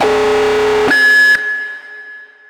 alarmClicheLoop.ogg